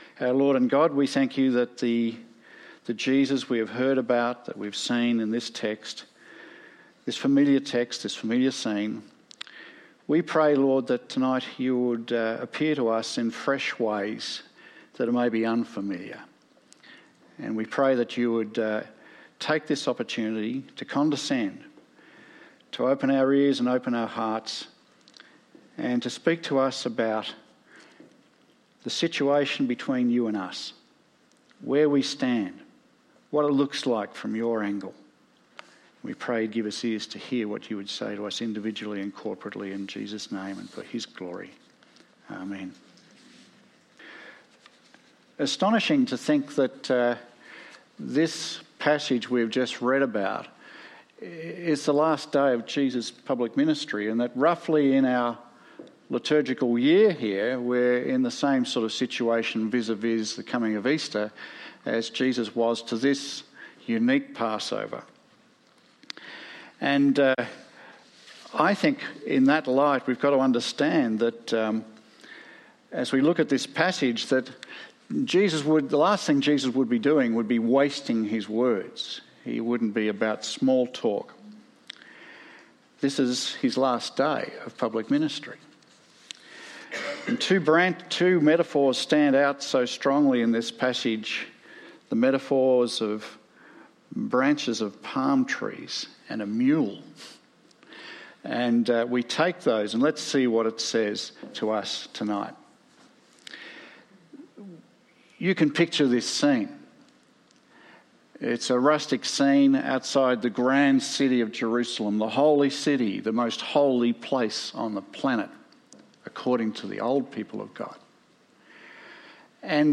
Sermons | St Alfred's Anglican Church
Guest Speaker